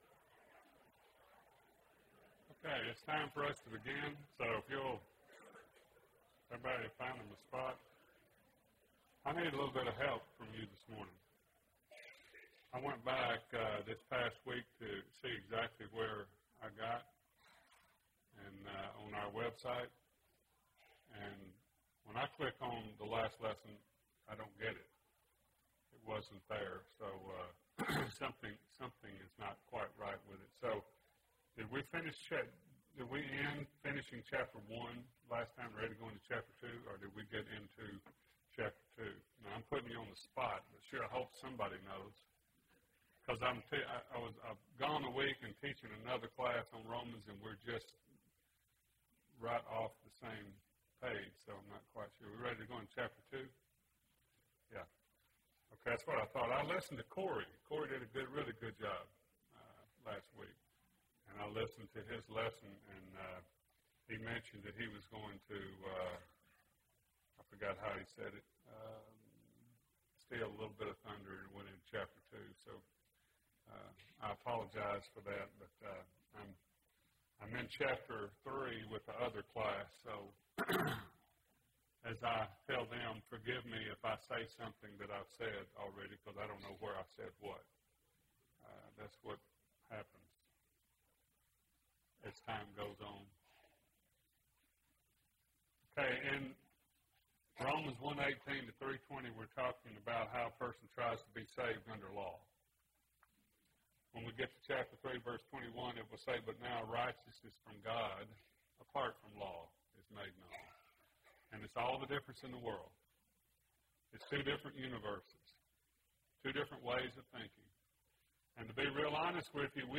Sunday AM Bible Class